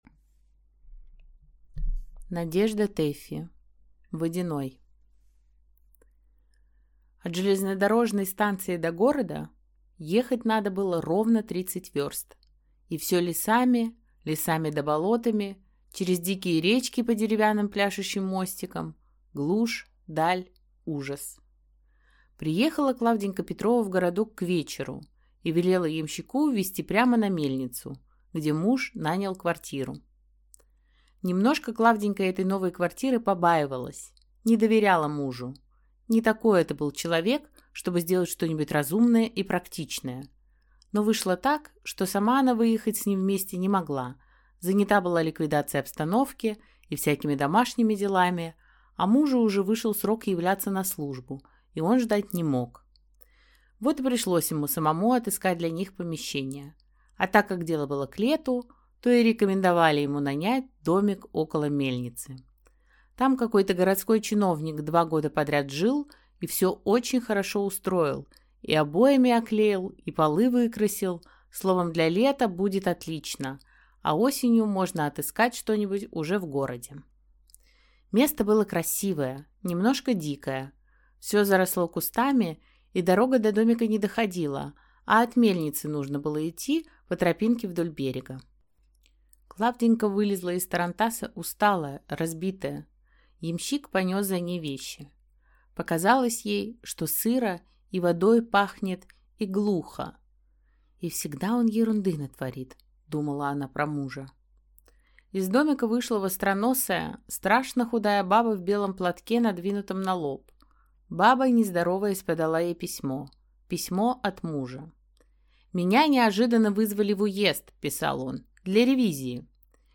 Аудиокнига Водяной | Библиотека аудиокниг